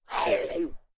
femalezombie_chase_05.ogg